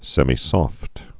(sĕmē-sôft, -sŏft, sĕmī-)